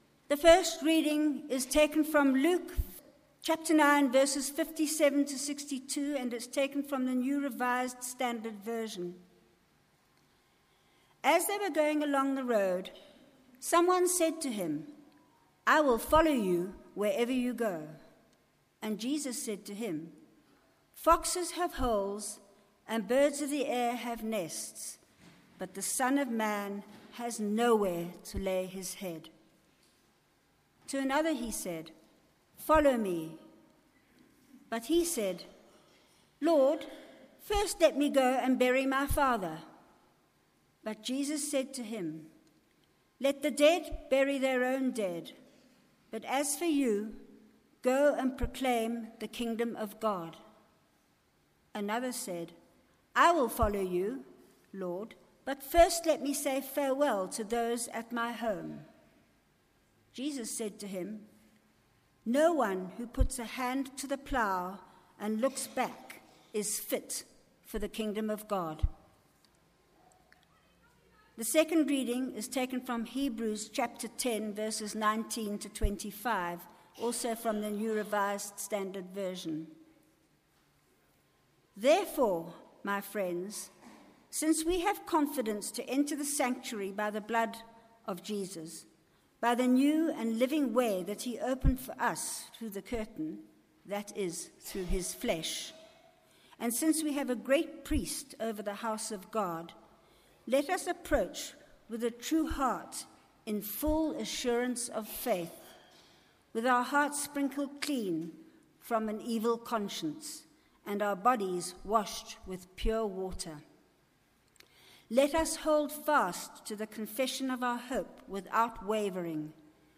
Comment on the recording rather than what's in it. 9:30am Fusion Service from Trinity Methodist Church, Linden, Johannesburg